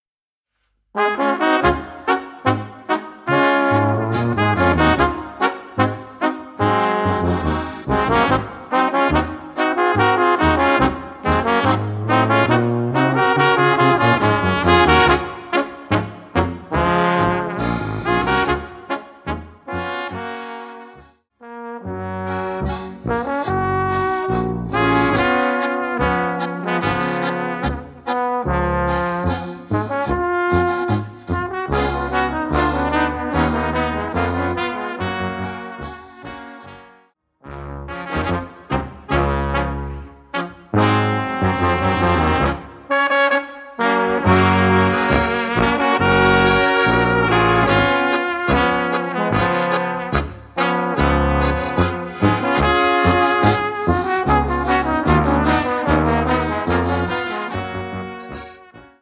Gattung: Solo für Flügelhorn & Tenor und Kleine Besetzung
Besetzung: Kleine Blasmusik-Besetzung